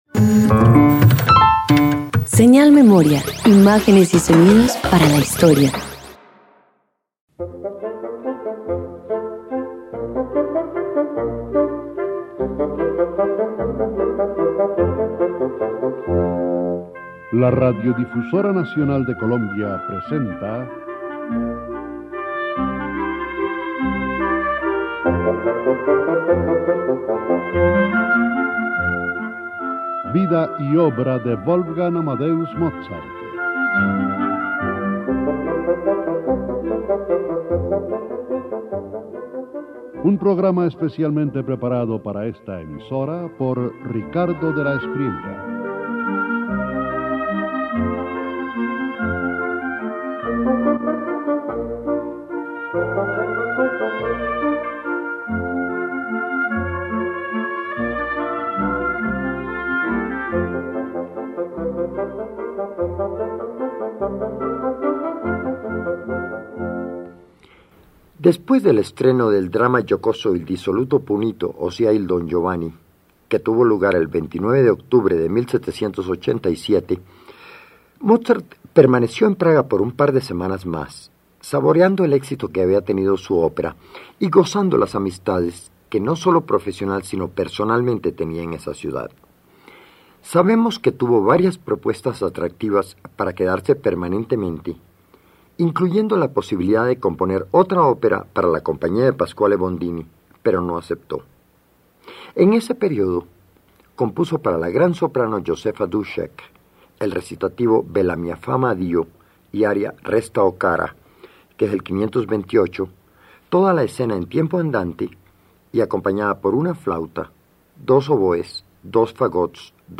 280 Harmonie musik apartes para conjuntos de vientos_1.mp3